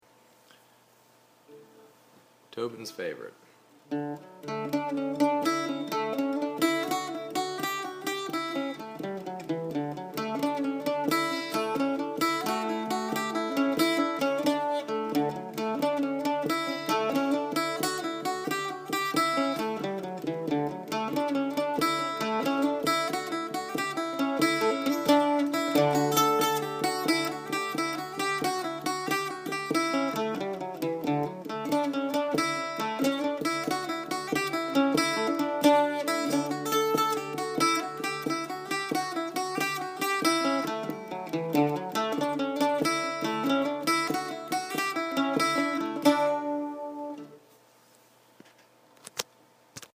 Tobin’s Favorite (jig in D)